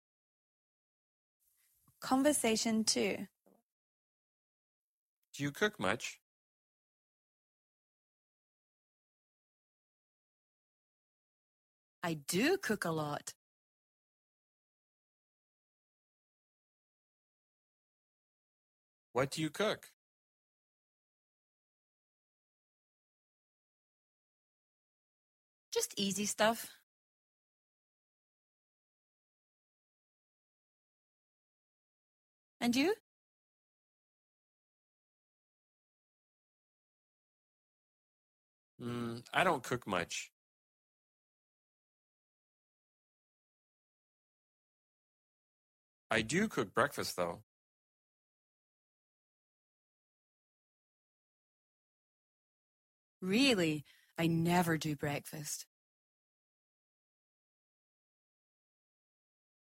Speak and Peek - Shadowing Play the audio below with short pauses.